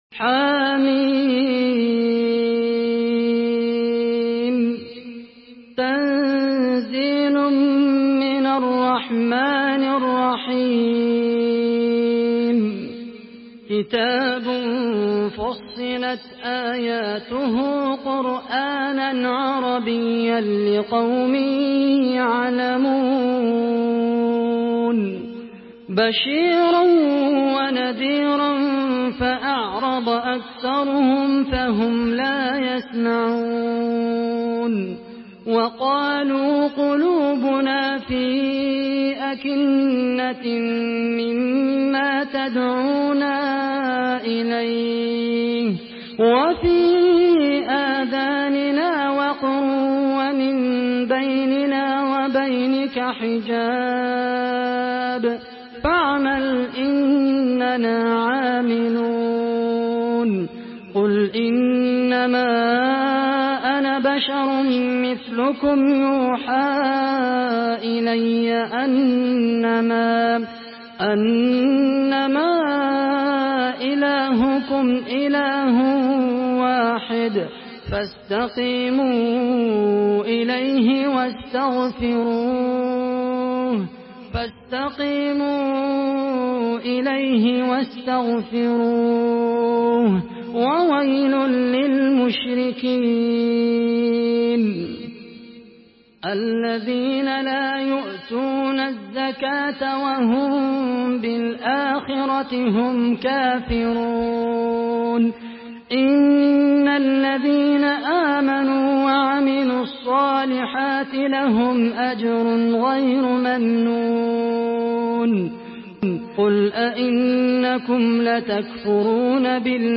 Surah Fussilet MP3 by Khaled Al Qahtani in Hafs An Asim narration.
Murattal Hafs An Asim